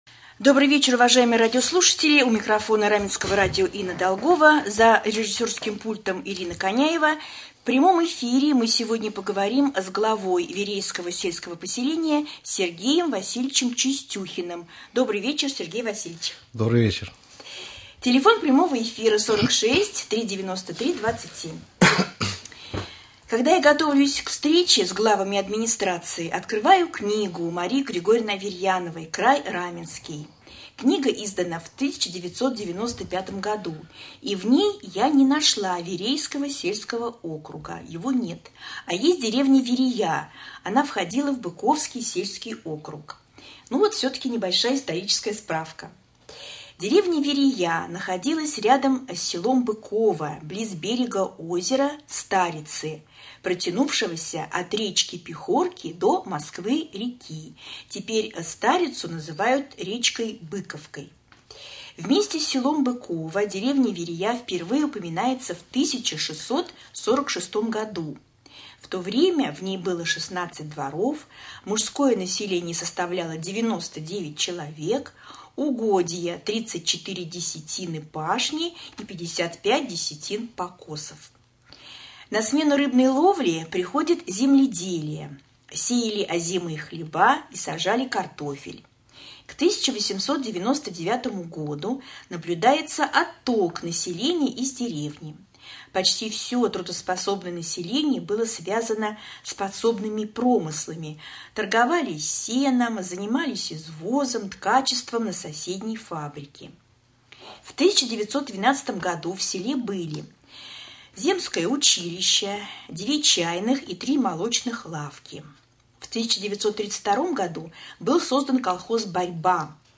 Прямой эфир с главой сельского поселения Верейское Сергеем Васильевичем Чистюхиным.